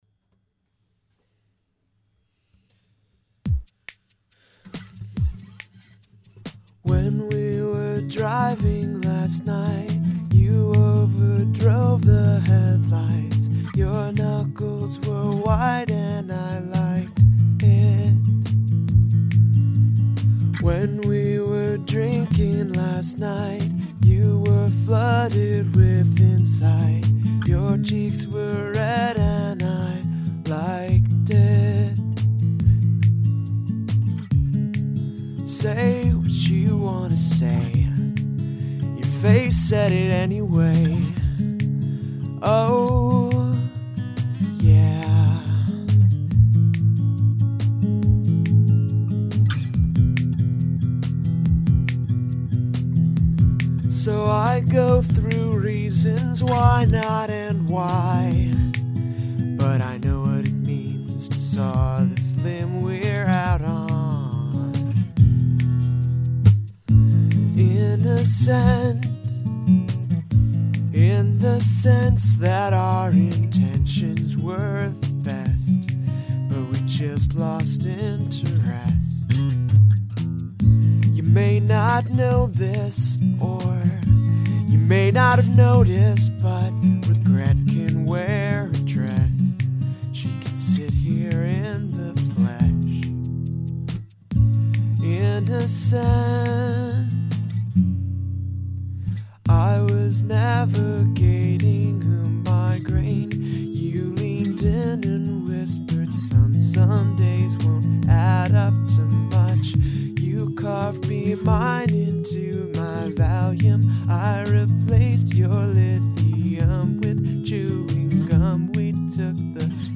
AA B C D A'A' B E DD'